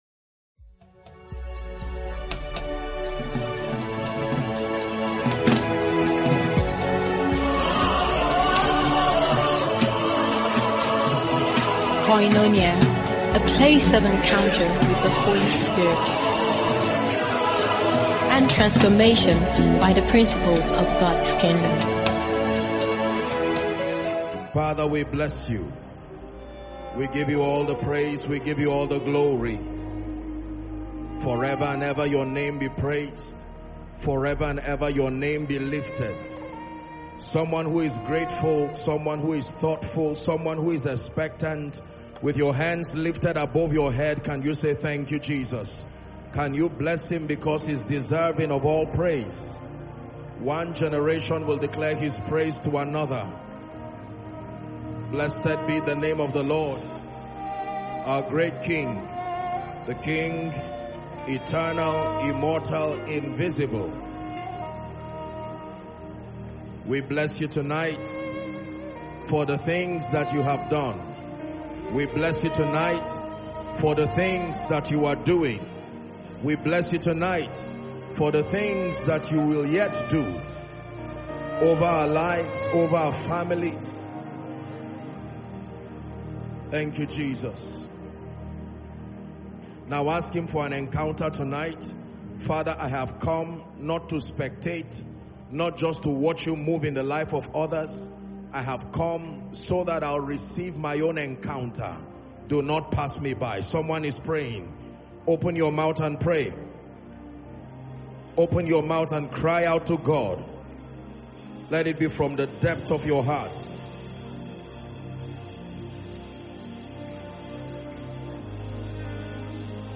The service opened with intense and heartfelt worship that ushered the congregation into a deep awareness of God’s presence. The atmosphere was saturated with reverence, expectancy, and spiritual hunger.